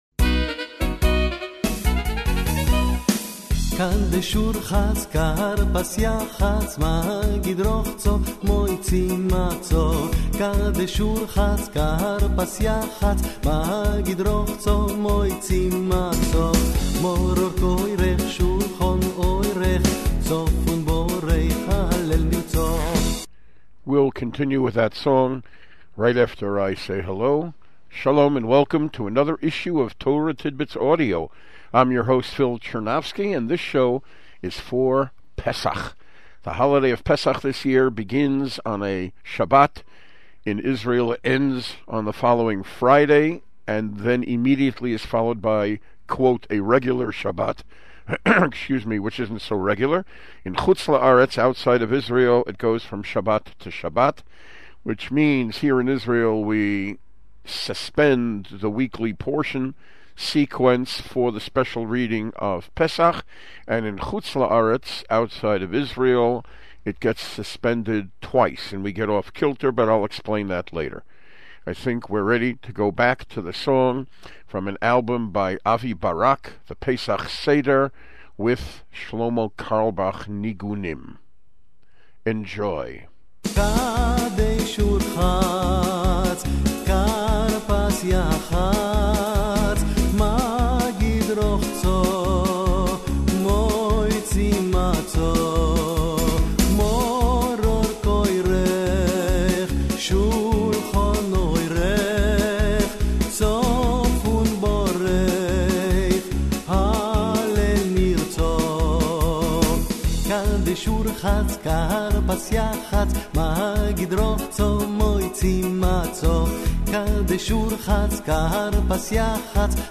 Seder Highlights in song and words